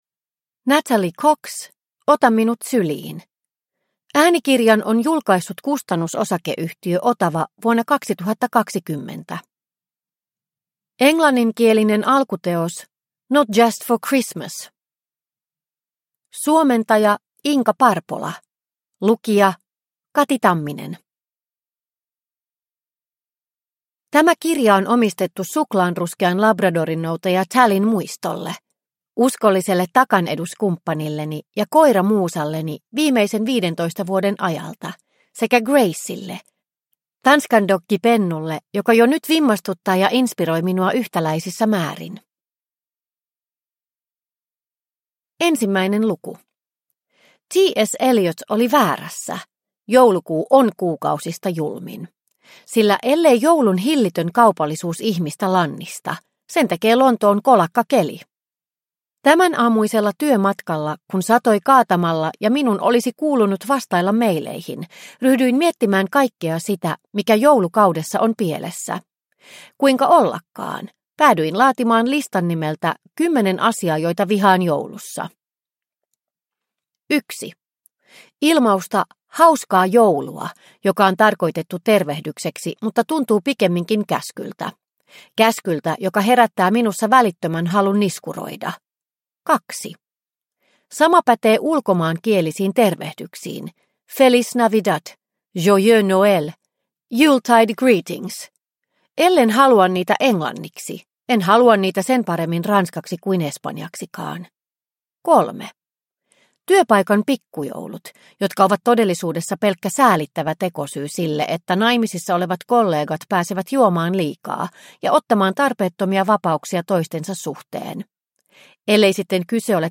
Ota minut syliin – Ljudbok – Laddas ner